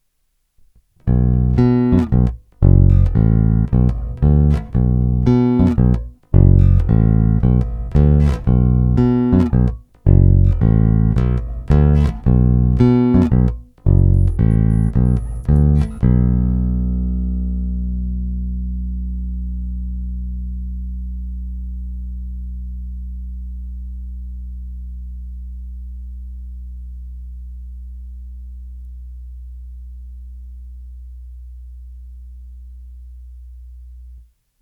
Není-li uvedeno jinak, jsou provedeny rovnou do zvukové karty s korekcemi ve střední poloze, dále jen normalizovány, jinak ponechány bez postprocesingu.
Krkový snímač